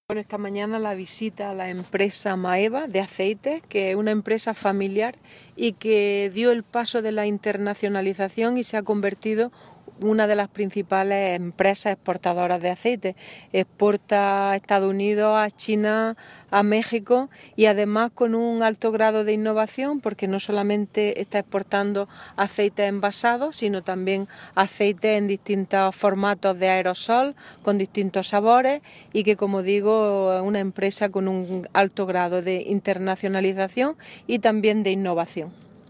Declaraciones de Carmen Ortiz sobre la empresa Aceites Maeva